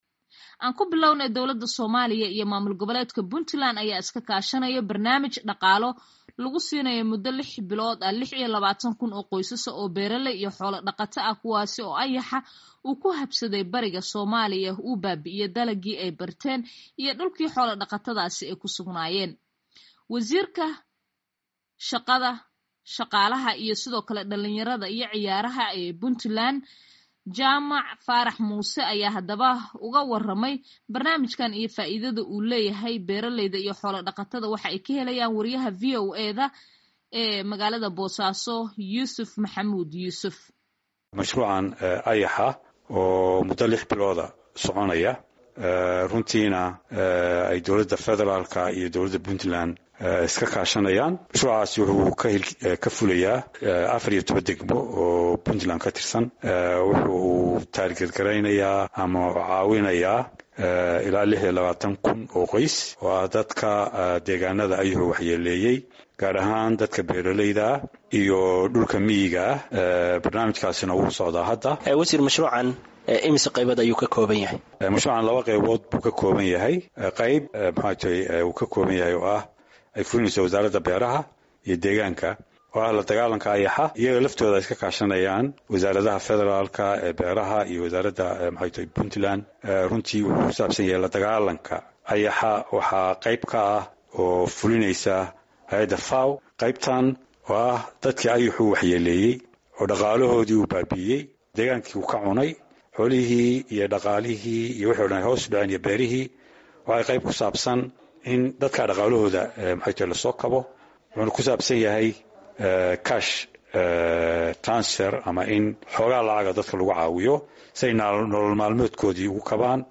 Wasiirka shaqada, shaqaalaha, iyo dhalinyarada iyo ciyaaraha Puntland Jamac Faarax Muuse ayaa VOA uga waramay barnaamijkan iyo faa’iidada uu u leeyahay beeraleyda iyo xoolo dhaqatada.